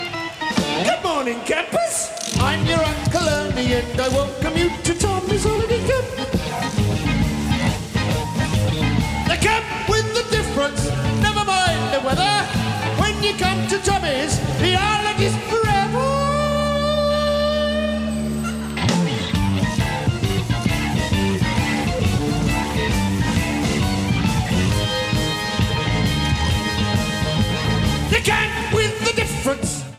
Pre-FM Radio Station Reels